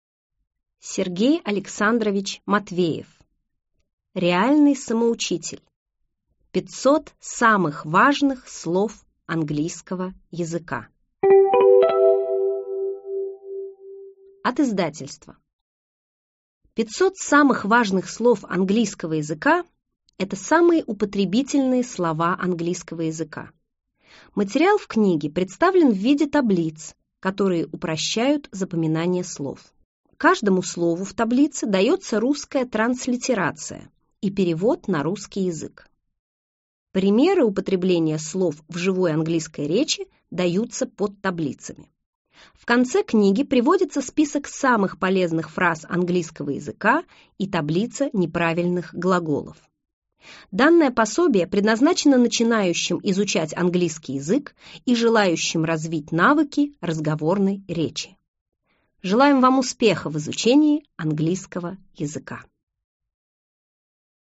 Аудиокнига 500 самых важных слов английского языка | Библиотека аудиокниг